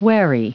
Prononciation du mot wary en anglais (fichier audio)
Prononciation du mot : wary